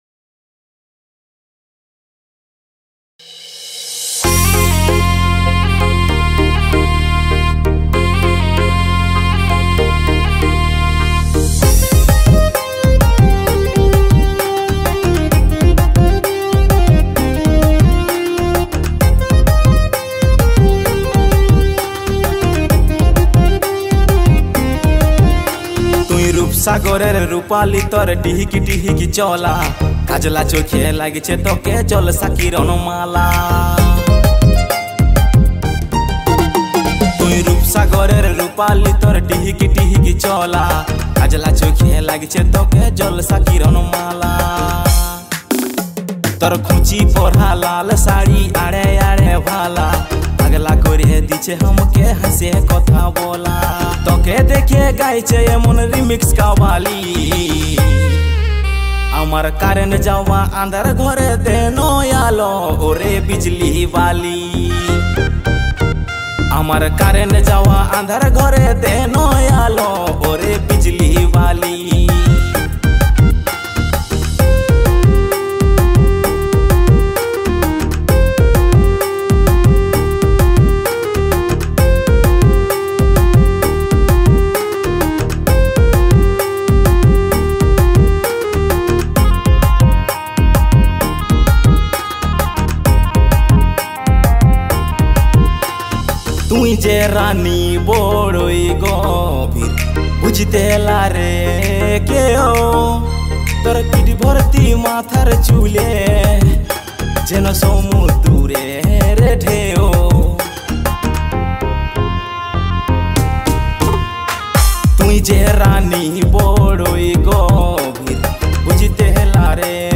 Releted Files Of Purulia